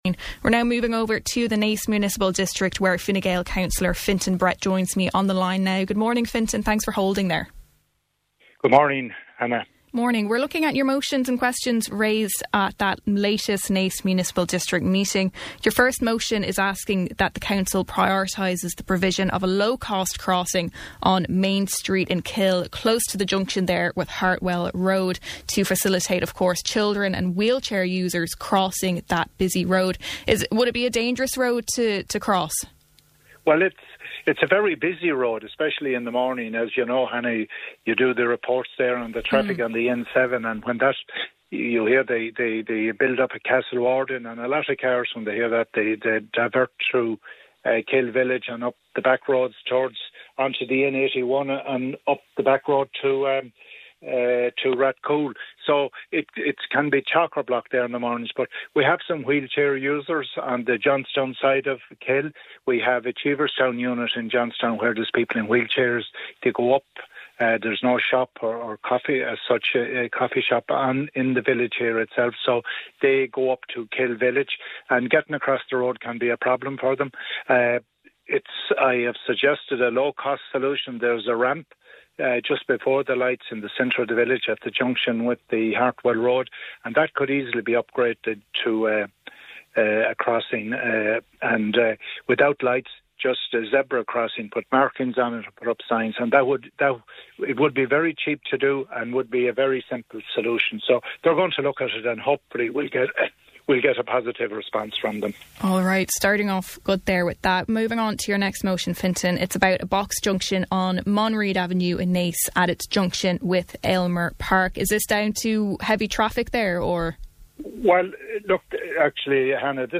Speaking on Kildare Today, Cllr Brett said that the council had removed the bus stop while seating was being installed in place of parking spaces in the town, but insisted that it didn't have any effect on the bus stop: